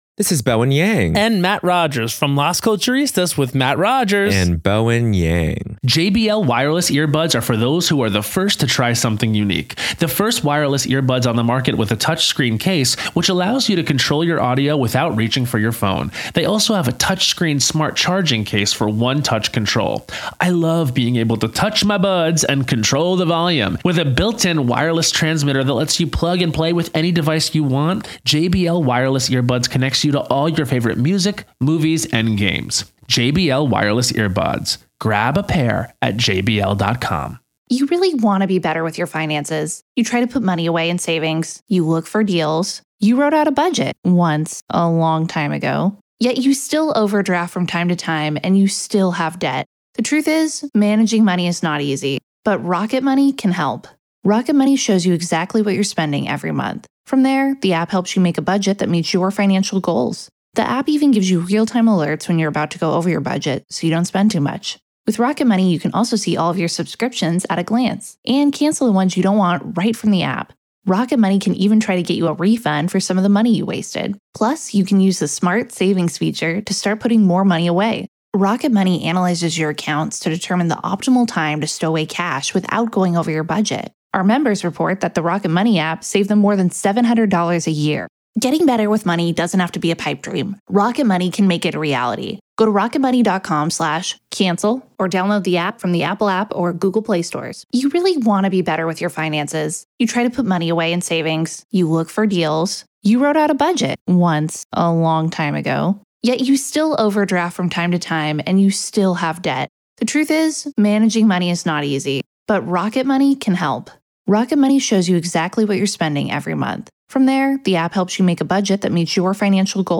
joins hands with a rotating roster of guests, sharing their insights and analysis on a collection of intriguing, perplexing, and often chilling stories ...